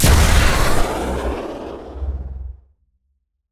ships / Combat / weapons